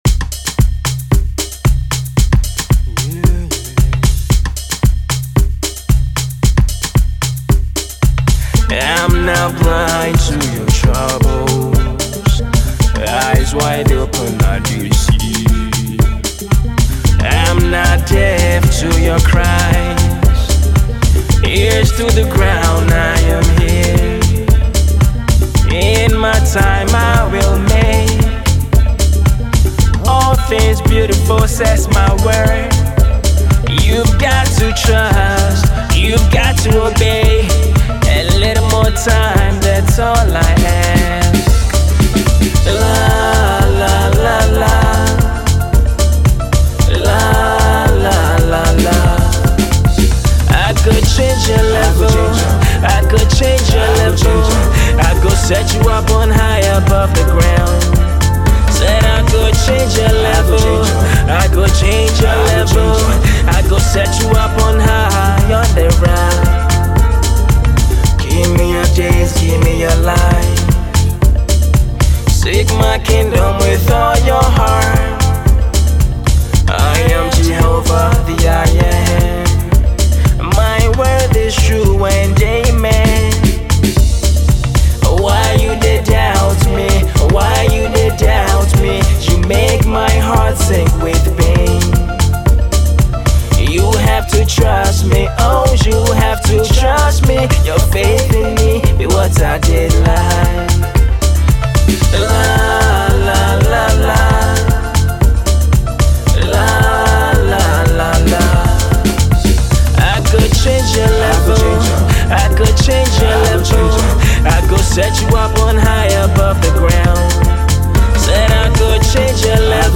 Ghanaian CHH group